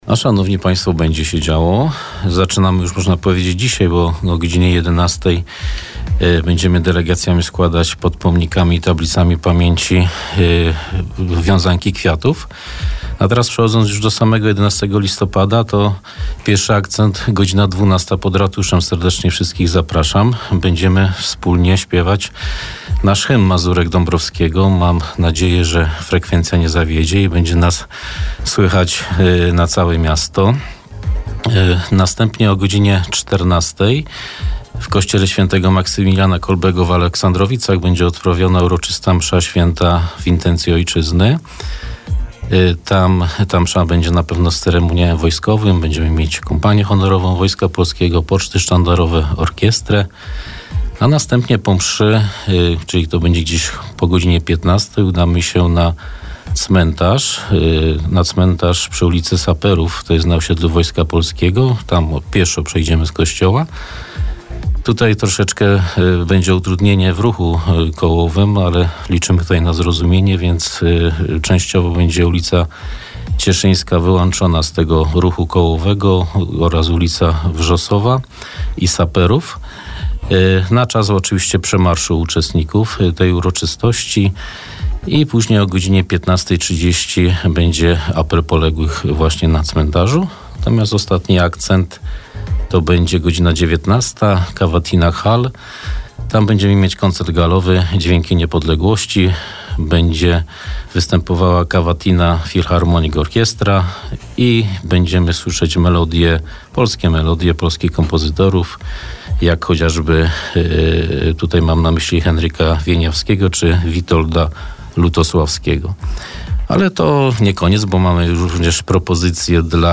O świętowaniu w Bielsku-Białej rozmawialiśmy z wiceprezydentem miasta, Piotrem Kucią.